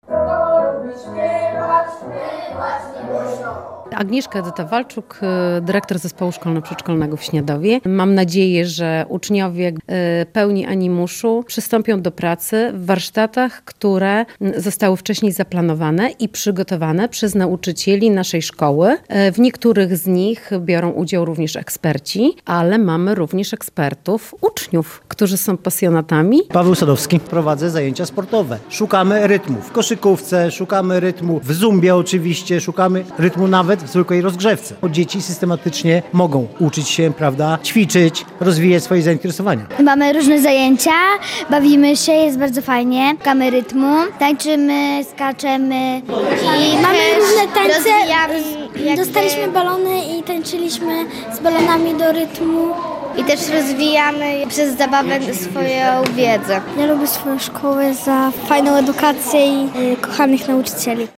Więcej w naszej relacji: